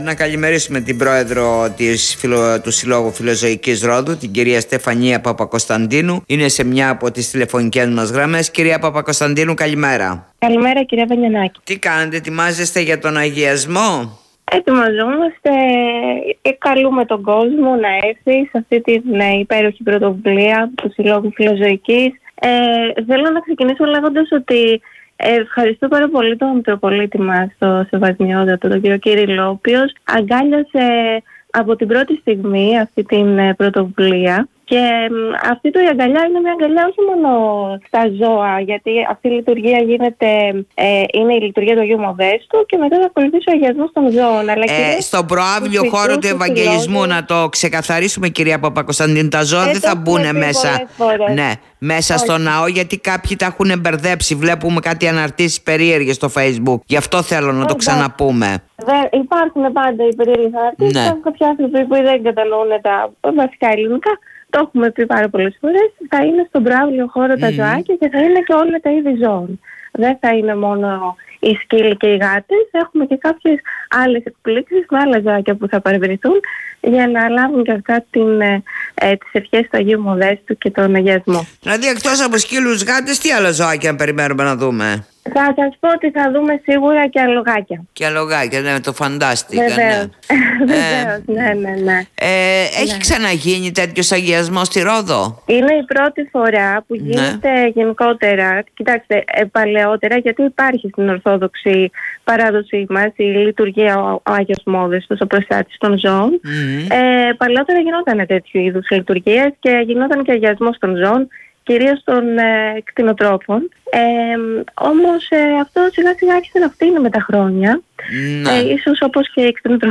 στον topfm